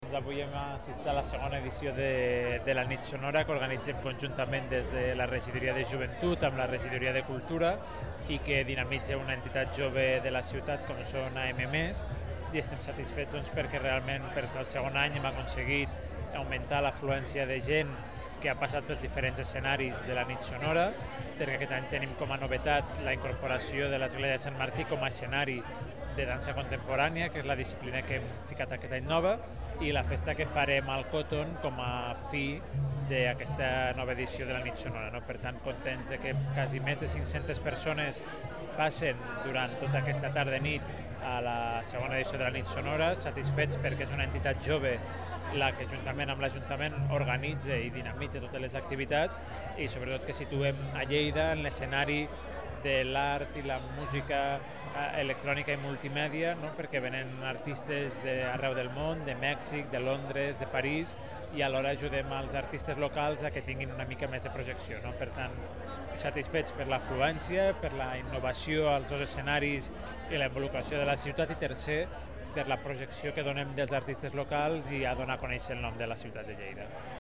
El regidor de Joventut, Oriol Yuguero, s’ha mostrat satisfet amb el resultat de Nit Sonora 2010, ja que ha augmentat l’afluència de gent i els nous espais han tingut molt bona acceptació. Yuguero ha recordat que es tracta d’un festival que vol mostrar el panorama de la música i cultura electrònica i contemporània, així com projectar artistes locals.
arxiu-de-so-doriol-yuguero